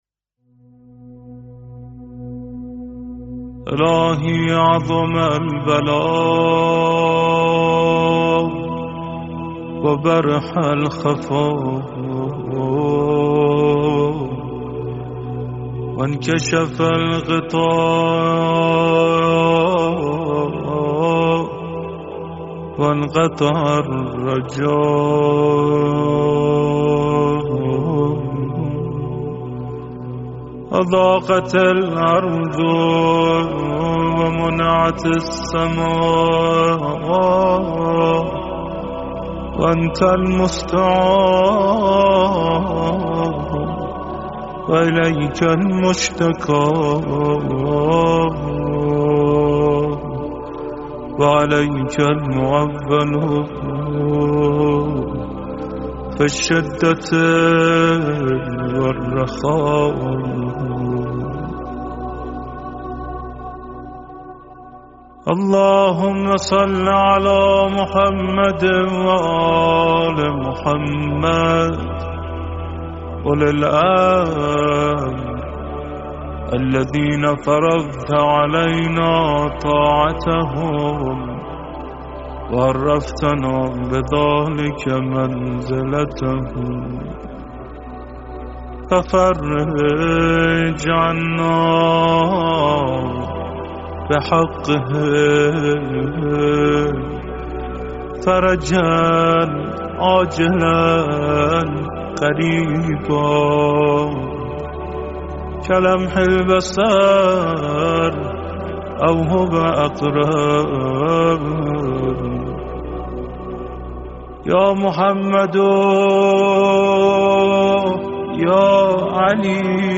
طلوع خورشید- قرائت دعای عظم‌البلاء (دعای فرج) برای ظهور امام زمان‌عج